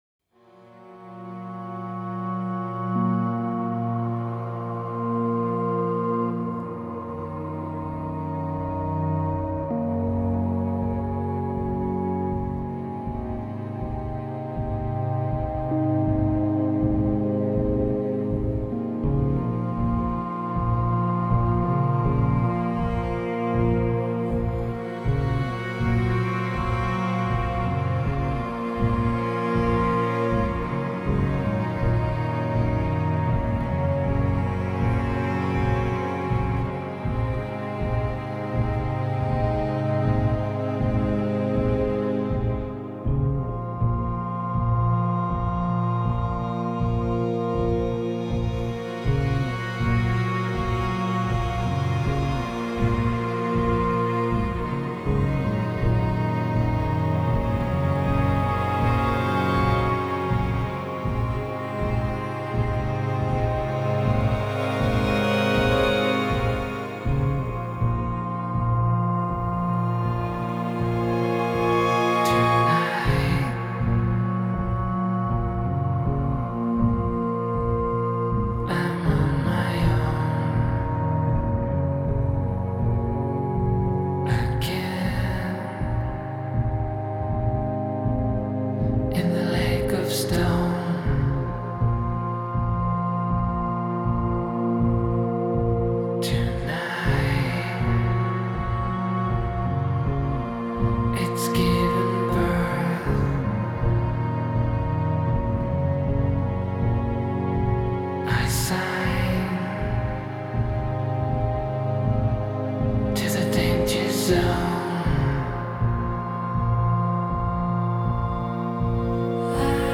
Genre: Electronic, Indie Pop